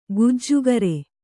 ♪ gujjugare